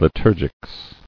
[li·tur·gics]